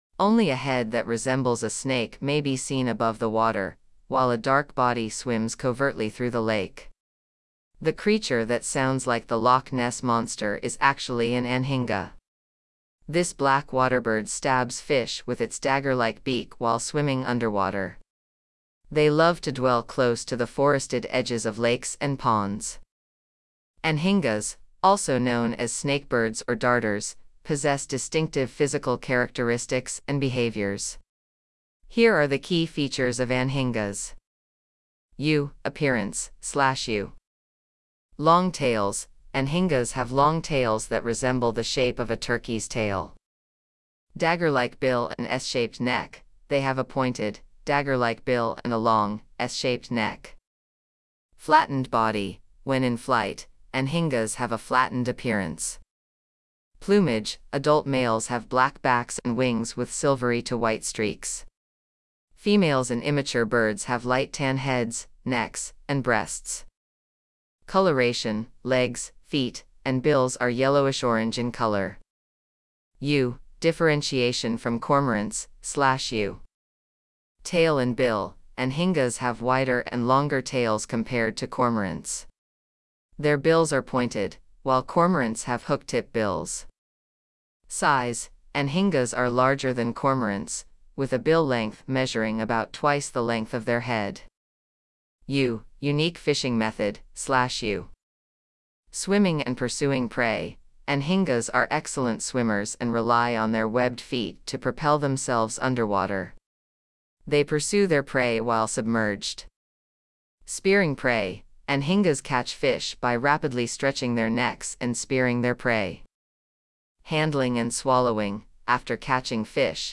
Anhinga
Anhinga.mp3